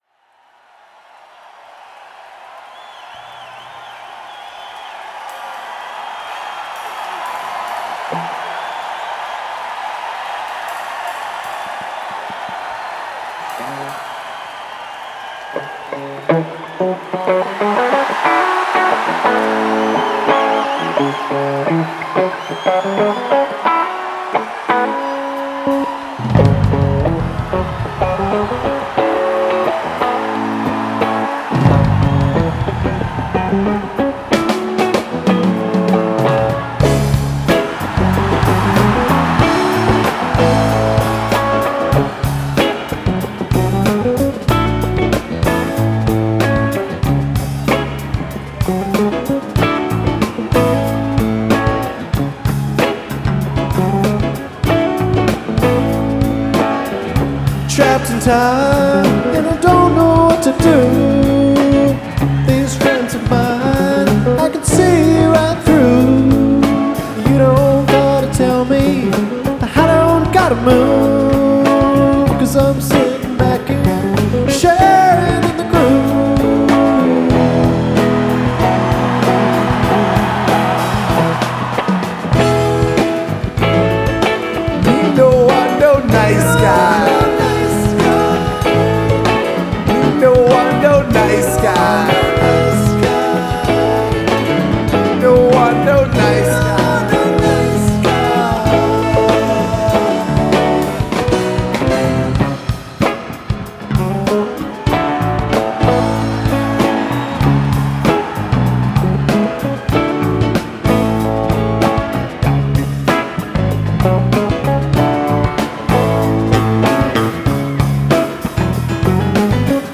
It's so full of groove and funk !